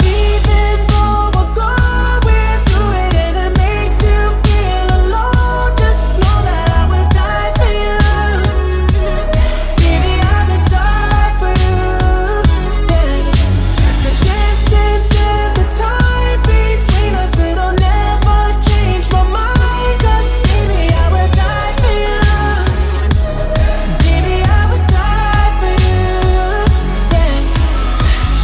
Genere: pop,ballads,downtempo,slow,hit